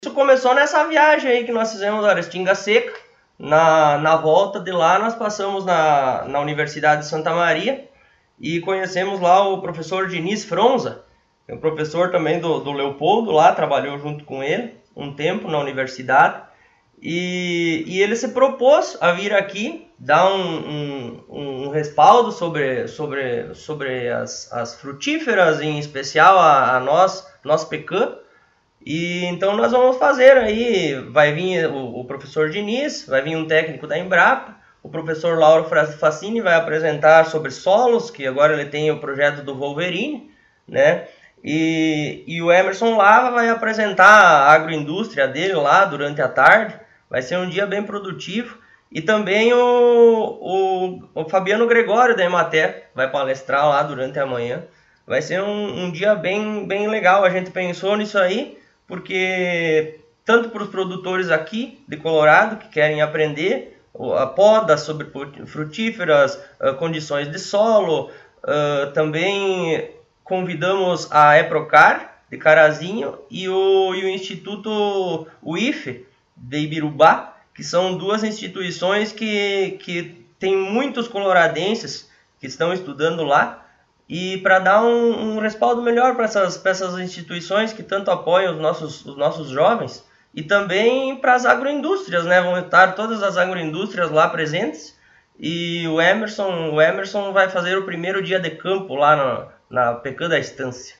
Presidente do Poder Legislativo, Taciano Paloschi, concedeu entrevista